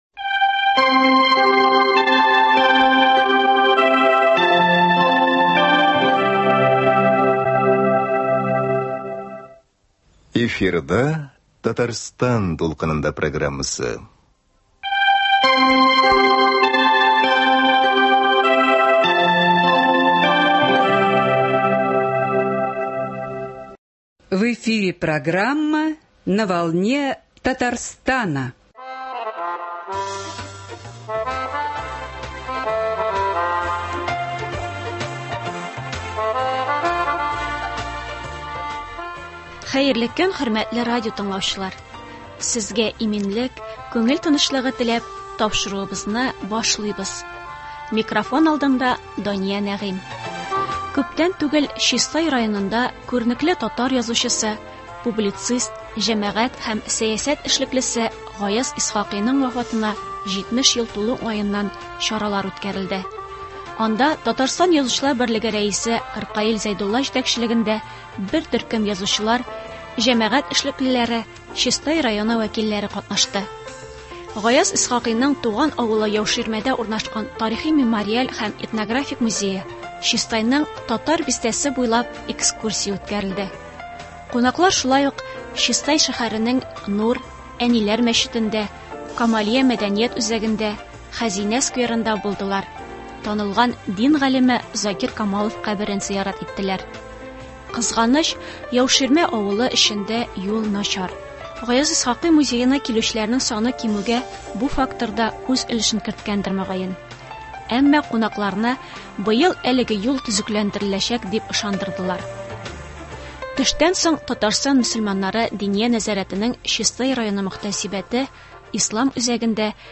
Хәзер сезгә шул чаралардан репортаж тәкъдим итәбез.